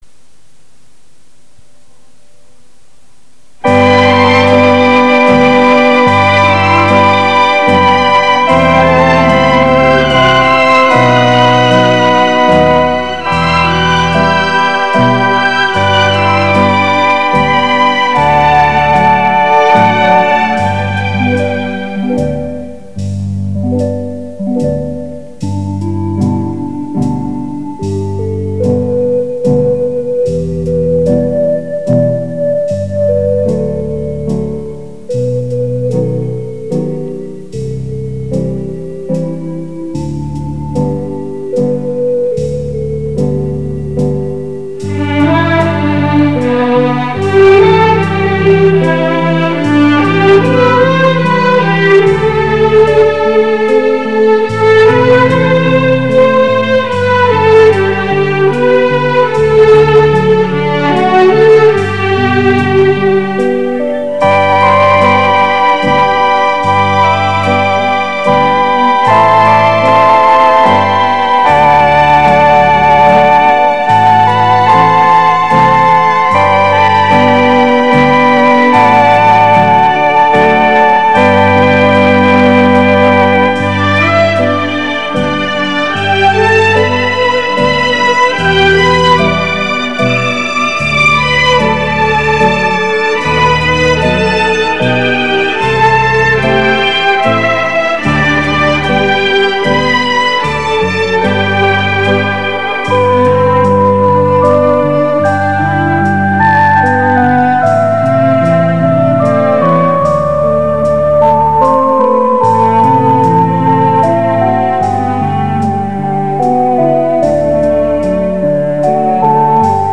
音频格式：磁带转256K MP3
温馨而怀旧。
磁带转录 音质有限。